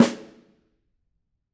Snare2-HitSN_v9_rr2_Sum.wav